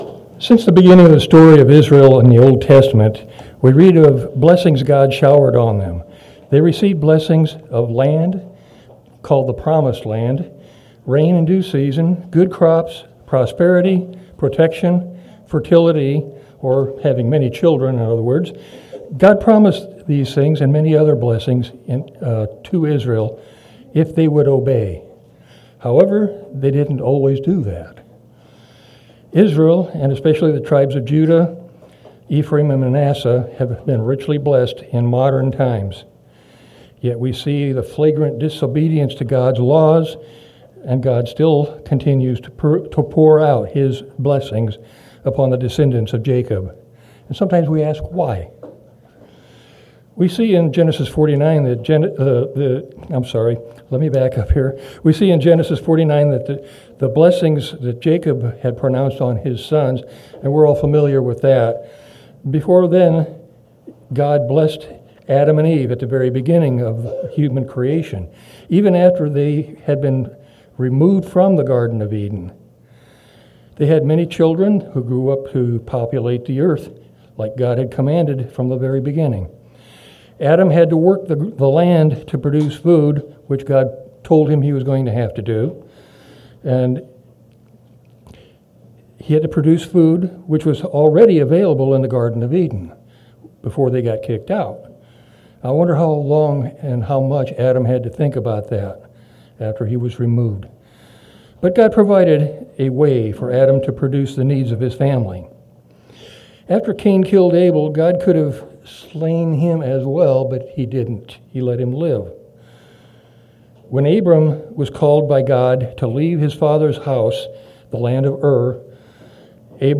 Sermons
Given in Omaha, NE